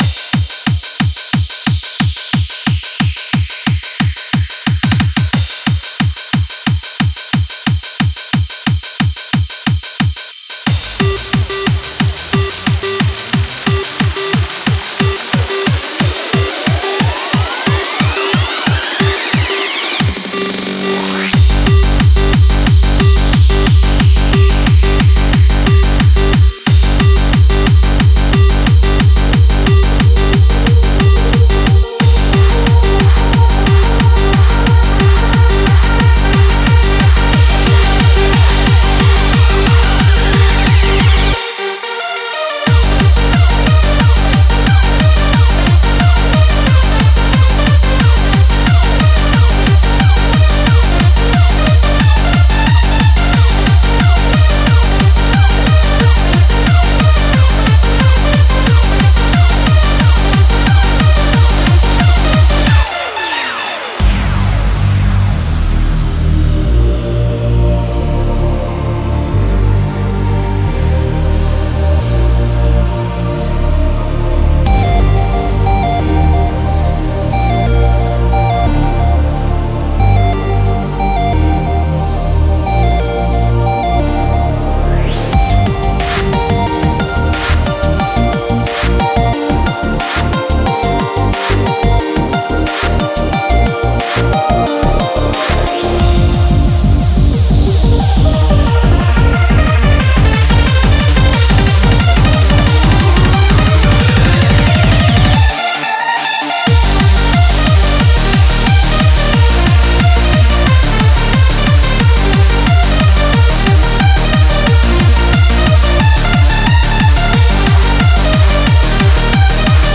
UK Hardcore